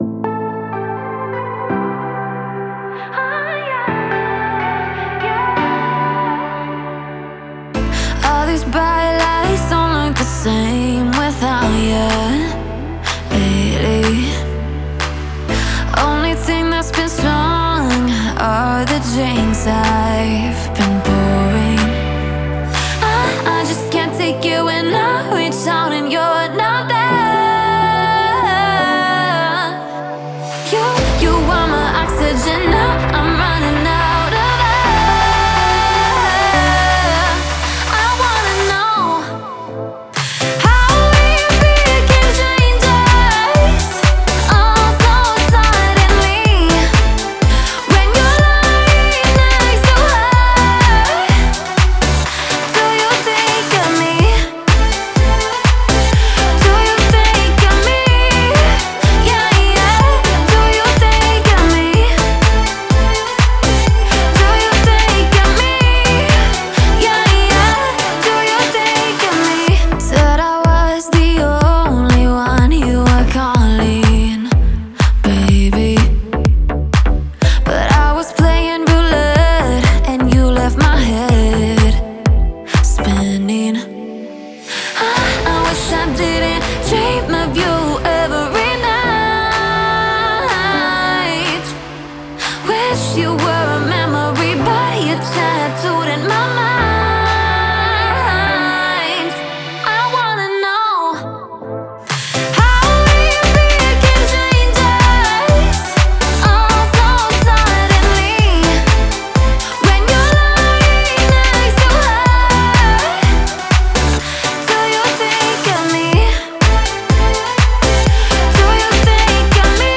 это яркий трек в жанре поп